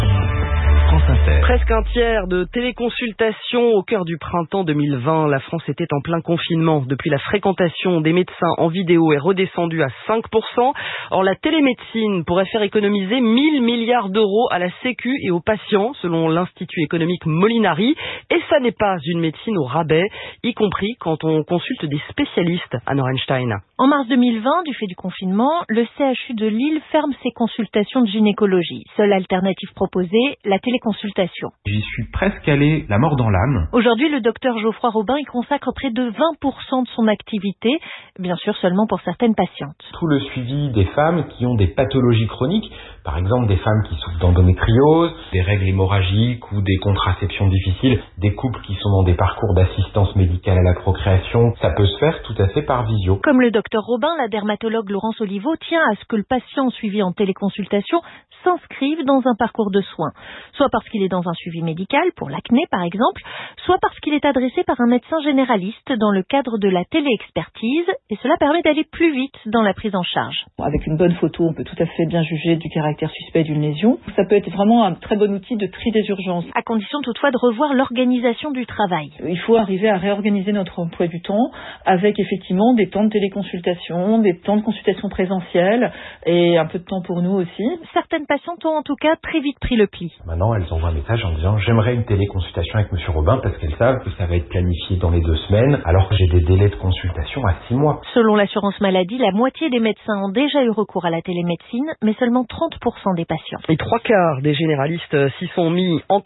Elle représentait presque un tiers des consultations à distance au pic du confinement de 2020 et seulement 5% maintenant, en dépit de ses avantages pour les malades. Un gynécologue et une dermatologue témoignent de l’intérêt de la téléconsultation et de la téléexpertise pour la priorisation des urgences, une plus grande réactivité ou le suivi des pathologies chroniques.